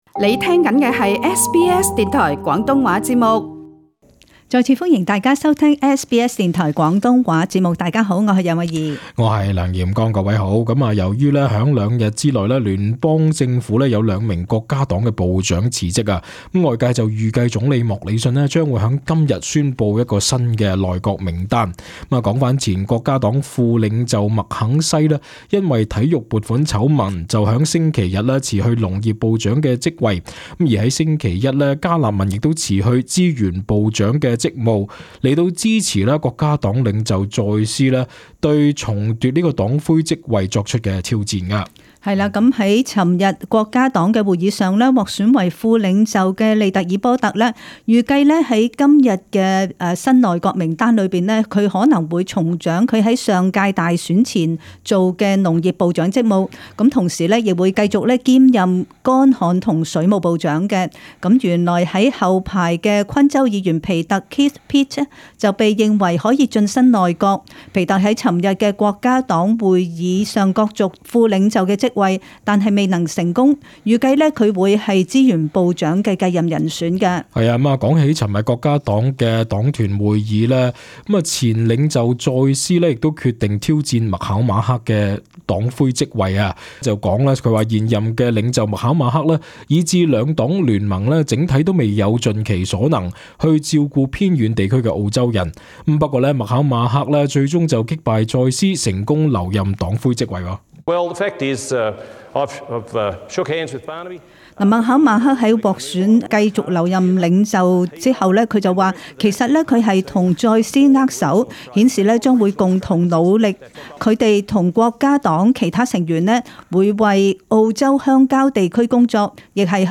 【时事报导】